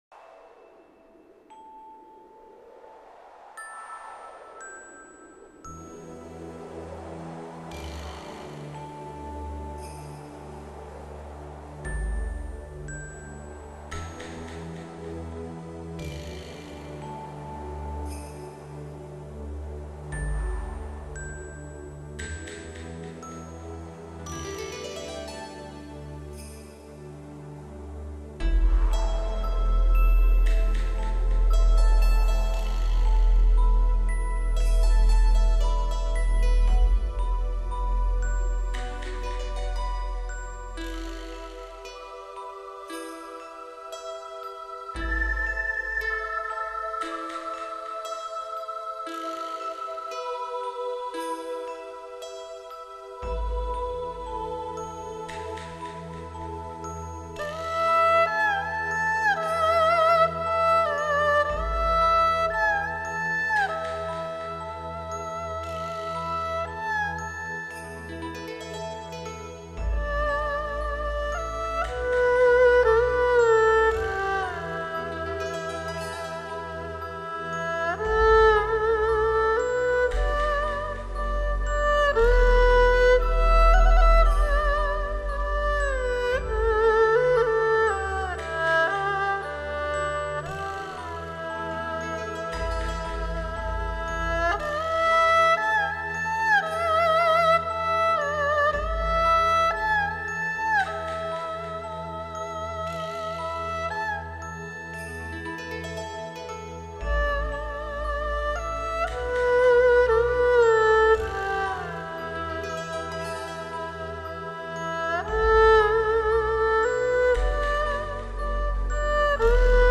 音乐类型:  二胡演奏